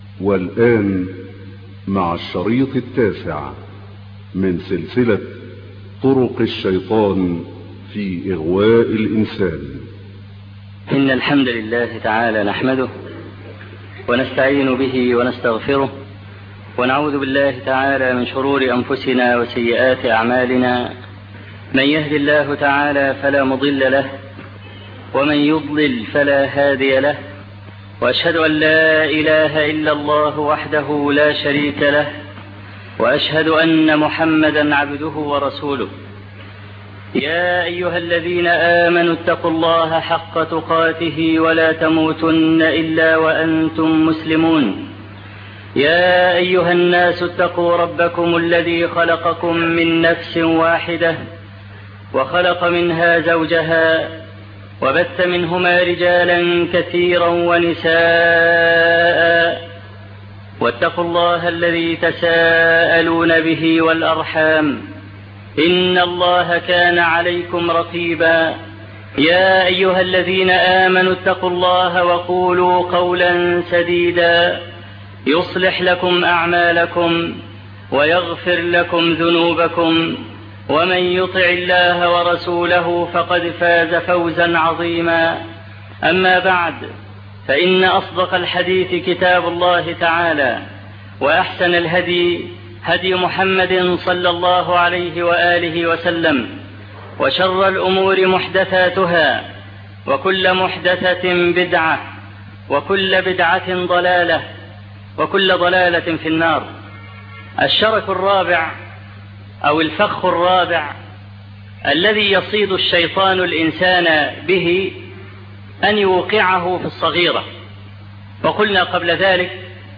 الدرس التاسع - الشيخ أبو إسحاق الحويني